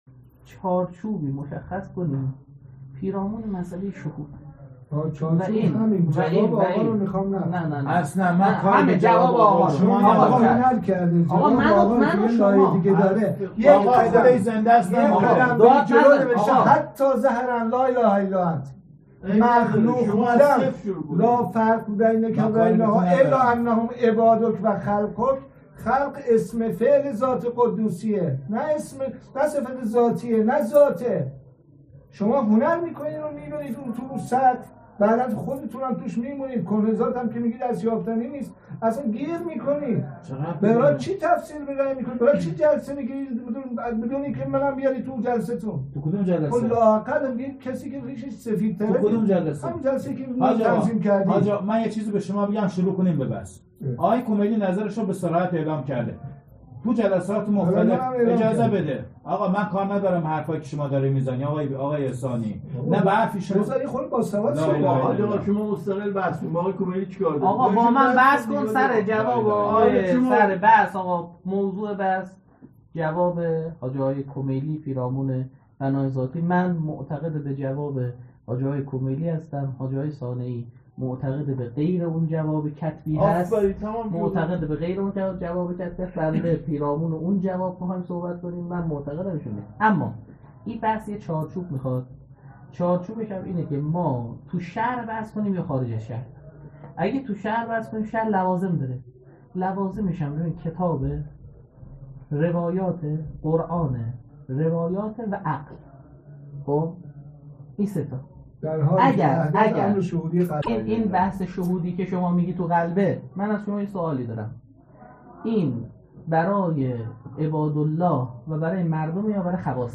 مناظره: بین تضاد کشف و شهود با شریعت کدام را انتخاب کنیم؟